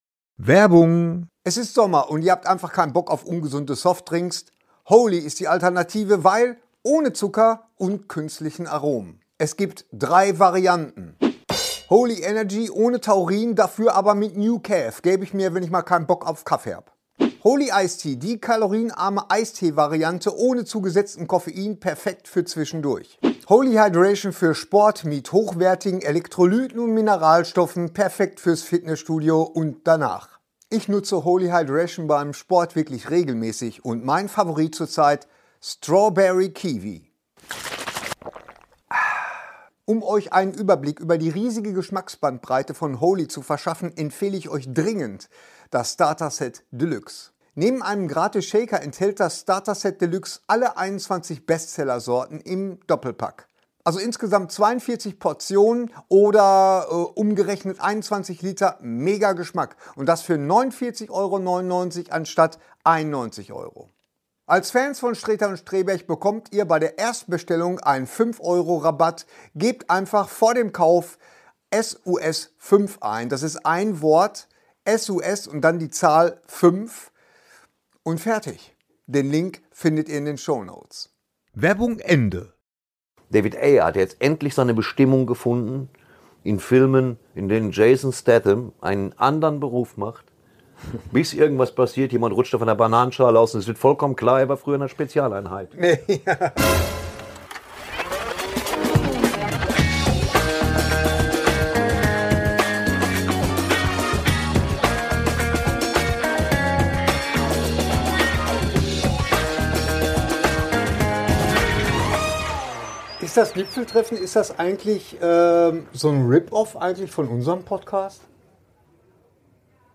reden, diskutieren und streiten mal wieder über die Dinge, die in der Nerd Welt Wellen schlagen.